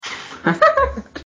Girl Laugh